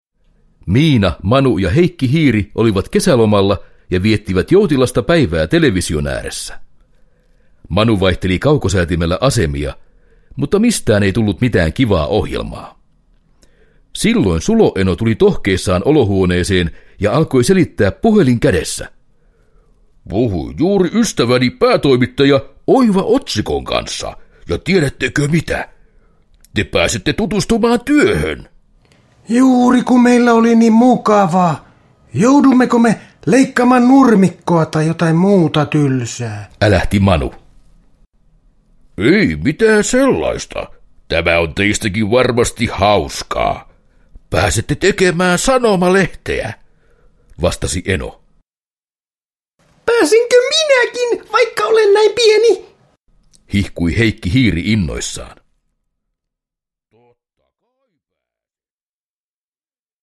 Miina ja Manu reporttereina – Ljudbok – Laddas ner